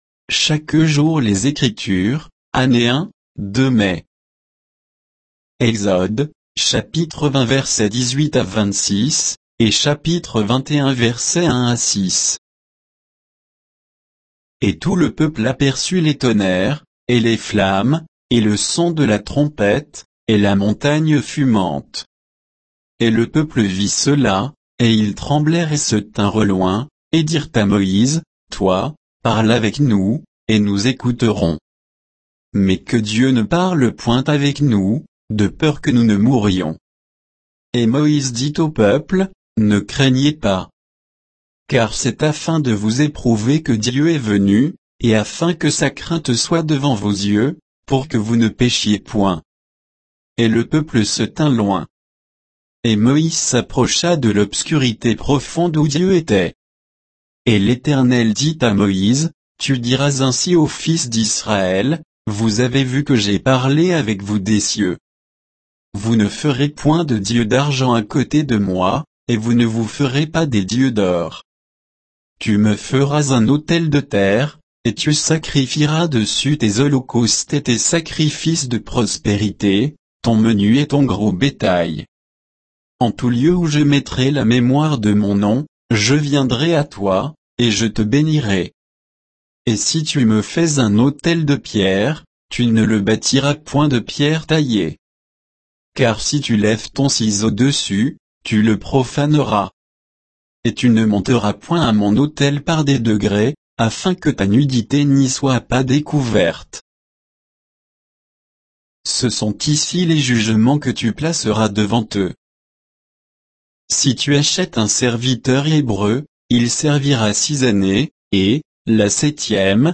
Méditation quoditienne de Chaque jour les Écritures sur Exode 20, 18 à 21, 6